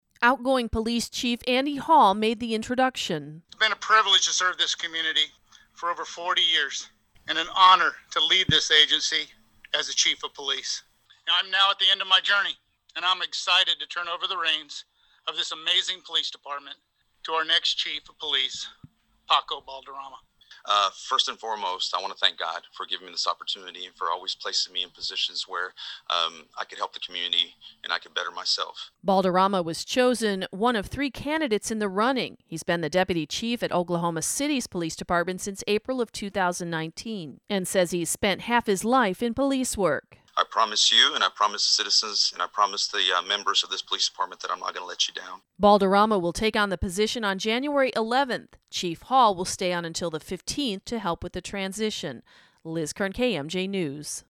During the Zoom press conference, 44 year-old Balderama said he’s spent half his life in police work, and is an 18-year veteran of the force.